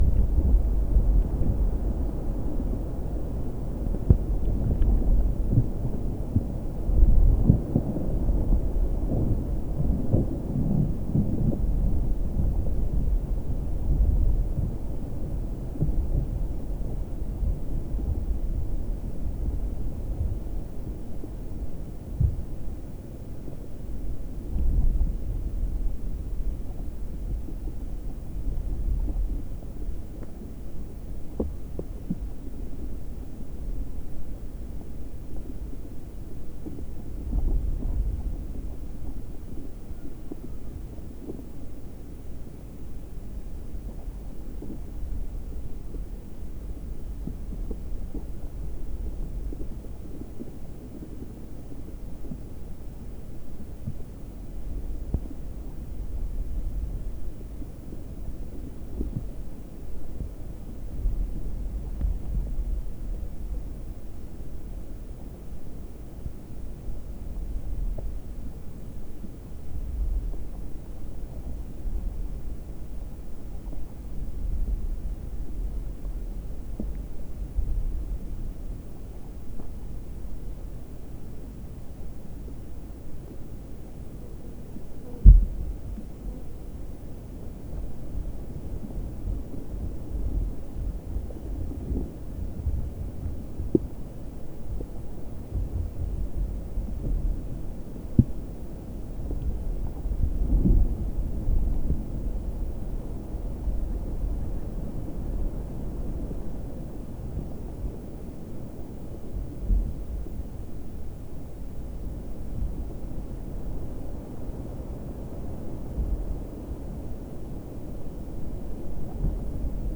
Hearing abilities aside, everyone had an opportunity to listen to the rustling, crackling and vibrating movements in the soil as the tree’s canopy was tossed around in a gusty south-westerly wind.
Recorded with a JrF contact mic buried about 6cm below the ground on the dripline of the tree, 21 September 2024